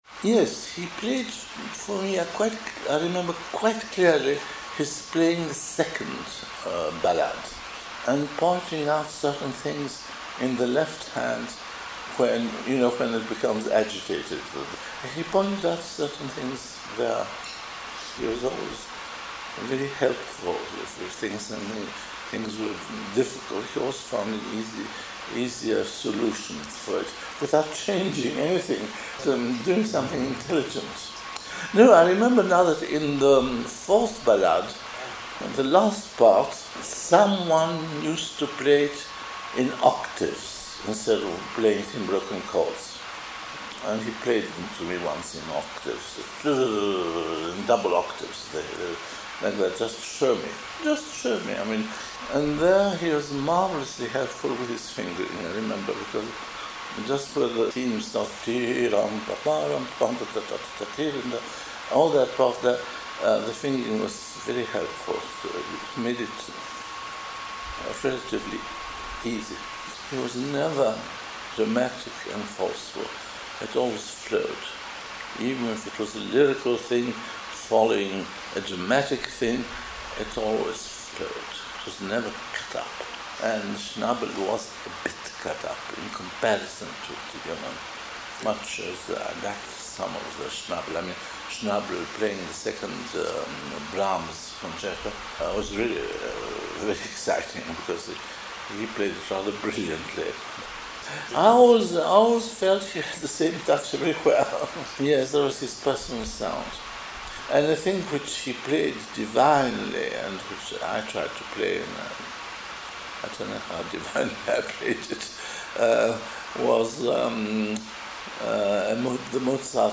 Here is a interview of a guy who speaks about his experience hearing Friedmann /uploads/default/original/3X/2/d/2d991baf86ff6b3e87816becacd207849bfc7001.mp3